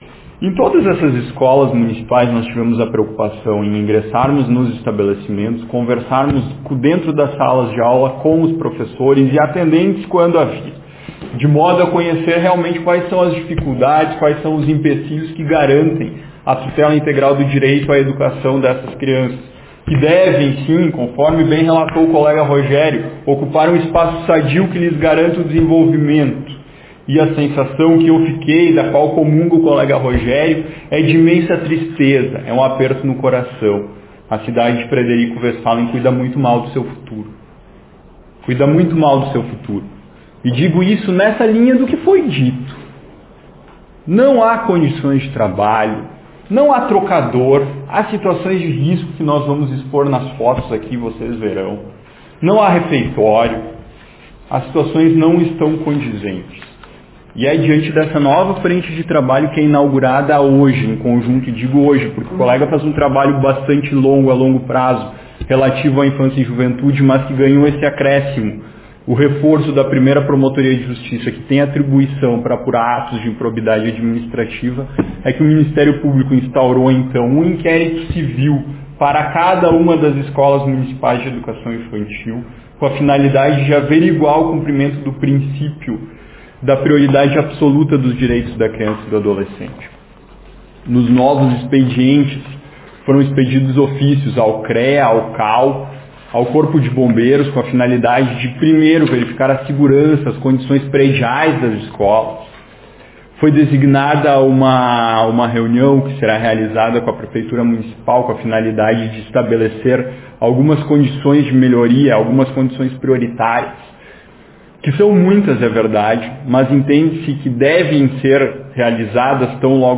Em coletiva de imprensa realizada na manhã de hoje, os promotores João Pedro Togni e Rogério Fava Santos, apresentaram situações precárias de diferentes educandários da cidade.
O promotor João Pedro falou sobre as visitas realizadas: